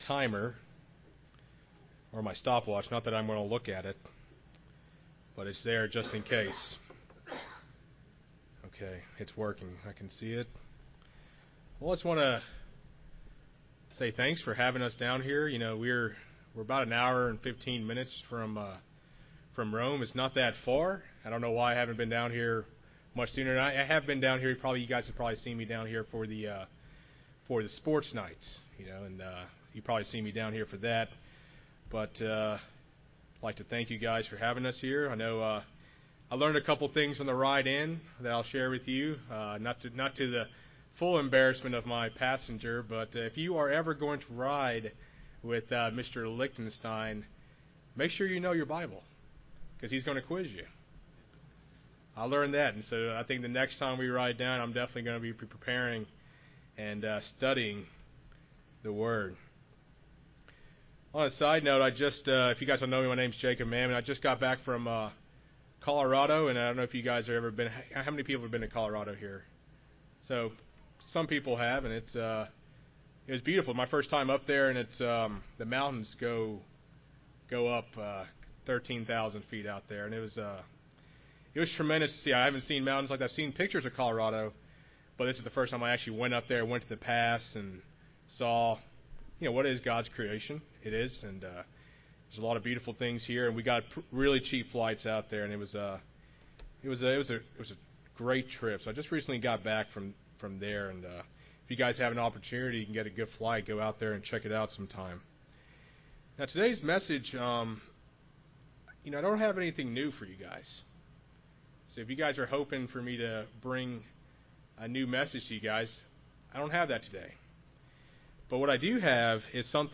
Given in Rome, GA
Print Three important questions we need to ask ourselves as christians UCG Sermon Studying the bible?